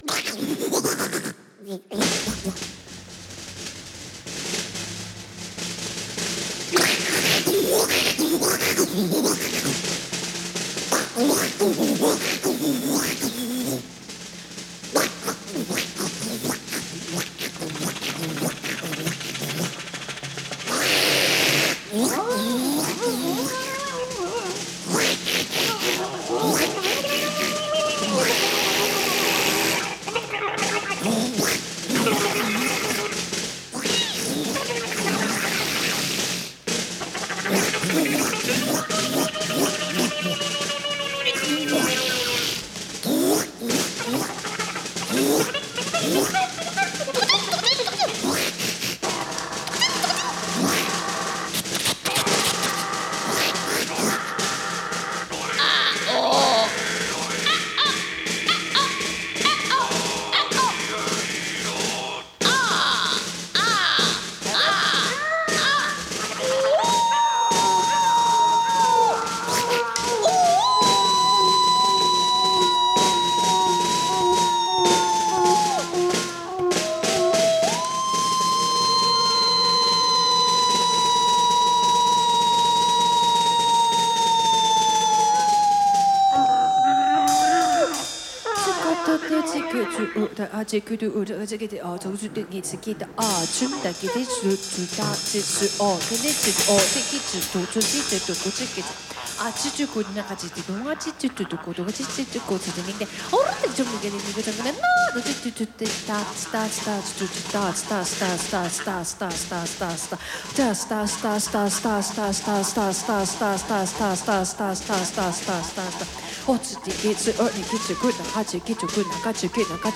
Recorded live at the 39th Street loft, Brooklyn.
drums, alto saxophone
voice
Mono (722 / Pro Tools)